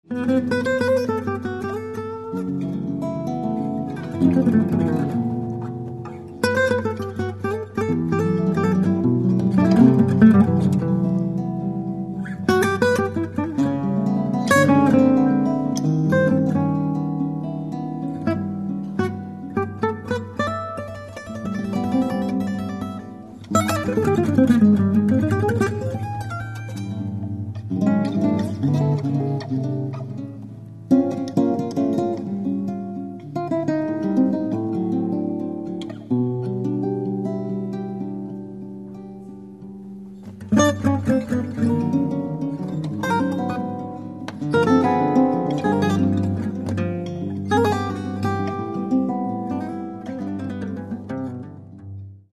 Ну а теперь издан диск с концертной записью
guitar
violin
bass